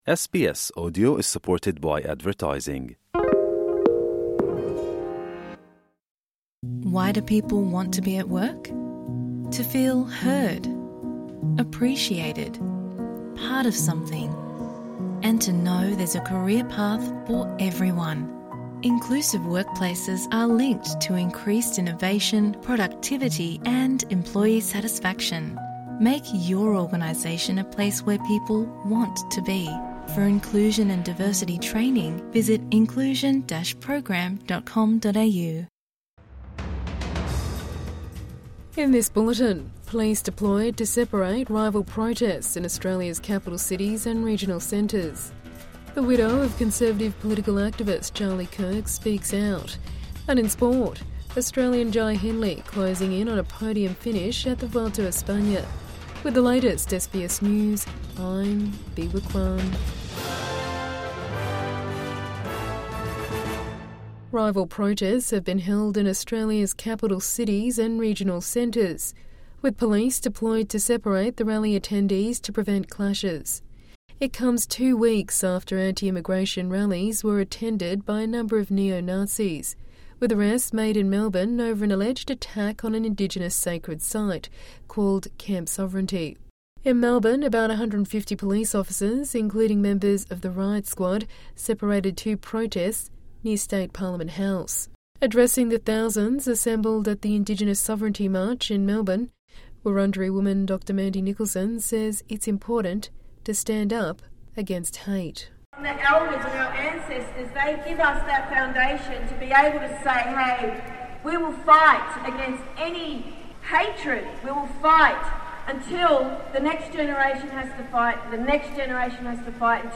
Evening News Bulletin 13 September 2025